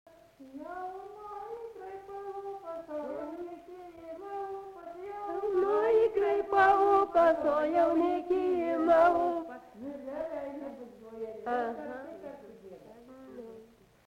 vokalinis
Dainuoja daugiau nei dvi dainininkės